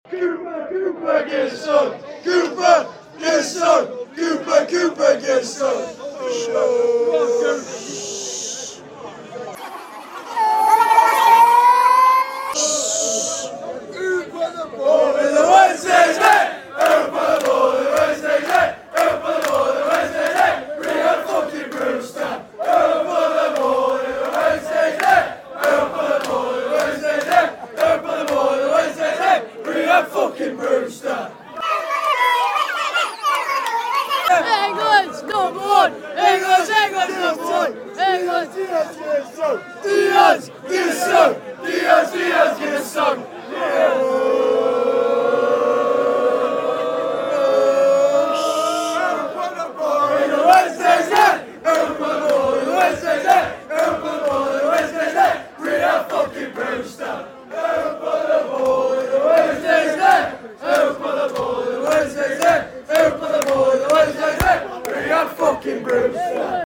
Upload By fan chants🔥